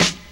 • Smooth Rap Steel Snare Drum Sample E Key 77.wav
Royality free snare single hit tuned to the E note. Loudest frequency: 3034Hz
smooth-rap-steel-snare-drum-sample-e-key-77-BTM.wav